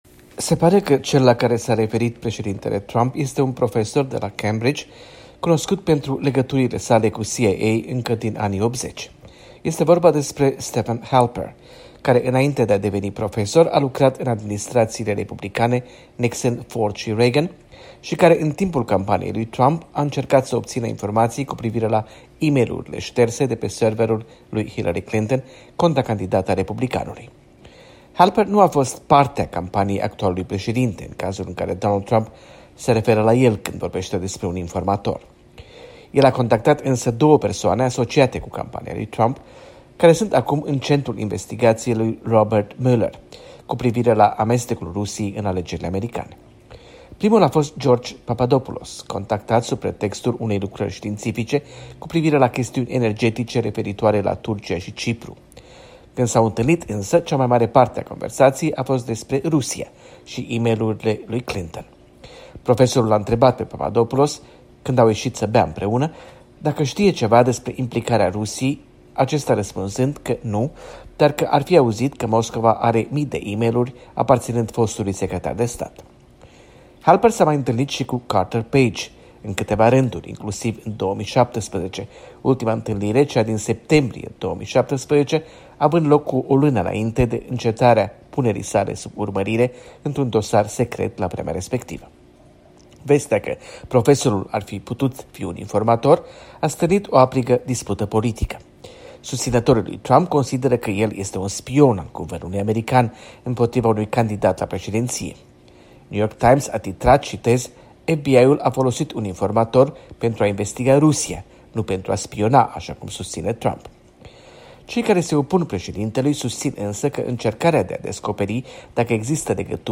Corespondența zilei de la Washington